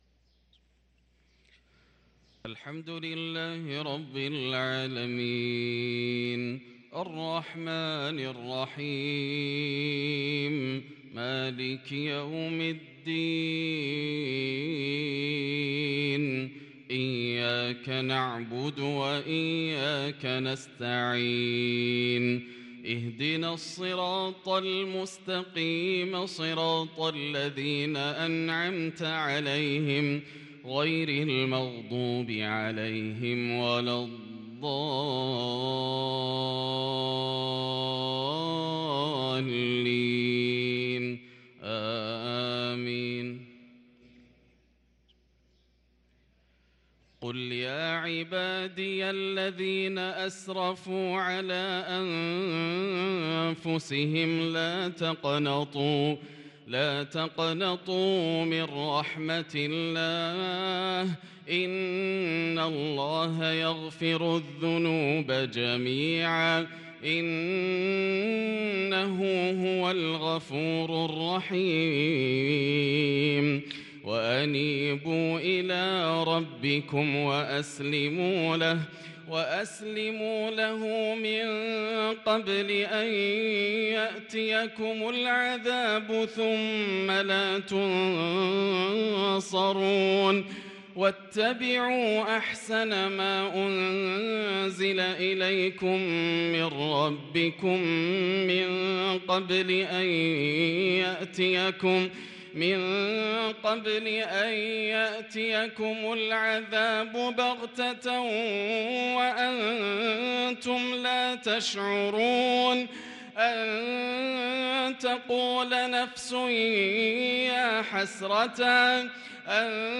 صلاة الفجر للقارئ ياسر الدوسري 9 جمادي الأول 1444 هـ
تِلَاوَات الْحَرَمَيْن .